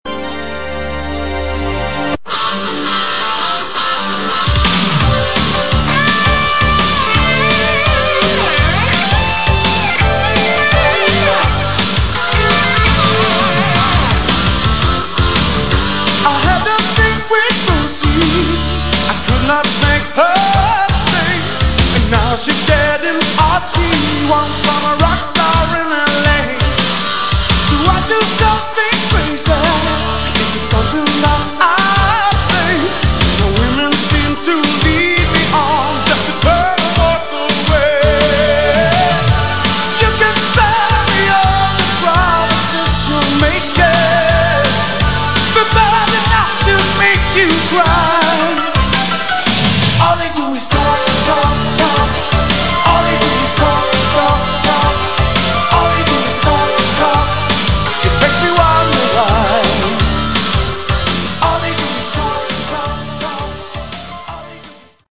Category: Glam/Hard Rock
guitars, keyboards
vocals
drums